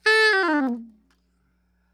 SOPRANO FALL
SOP SHRT G#4.wav